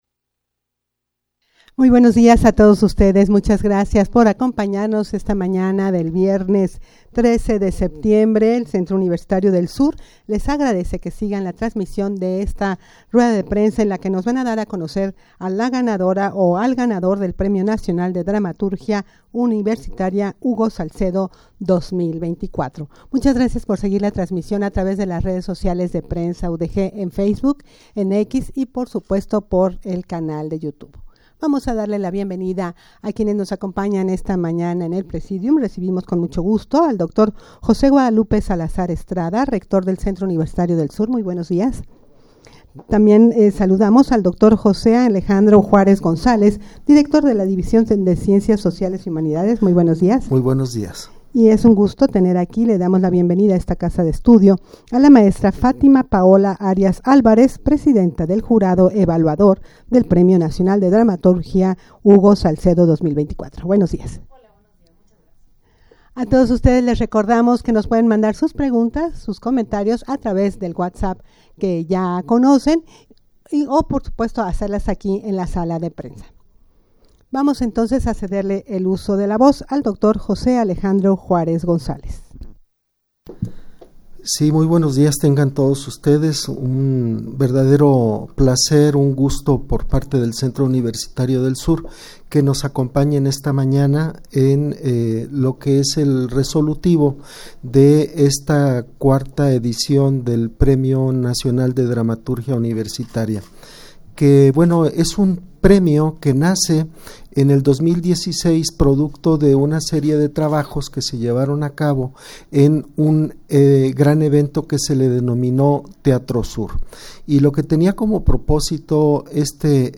Este 24 de septiembre será distinguido en la Casa del Arte en Ciudad Guzmán Audio de la Rueda de Prensa 24.06 MB Un estudiante y su profesor se encuentran donde no esperaban, en un punto clandestino donde se compra droga .